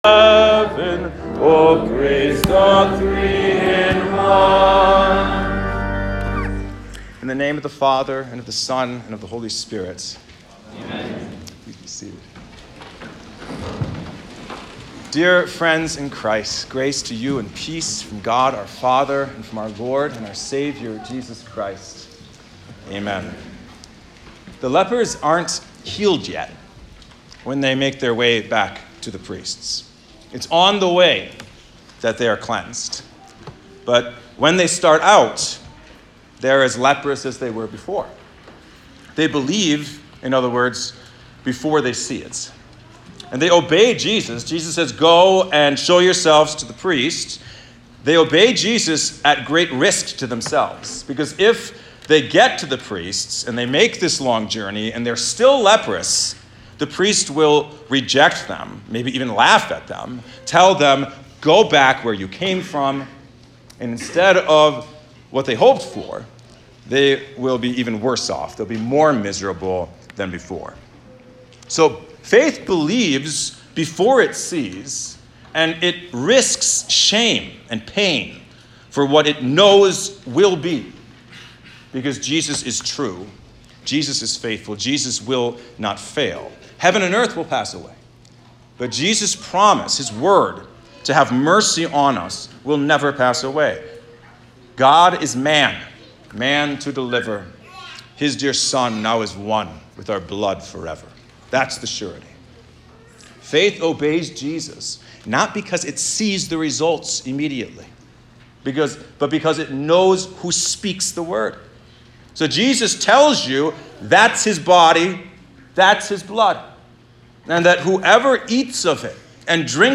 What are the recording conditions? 9-10-23 Trinity 14 • Mount Hope Lutheran Church & School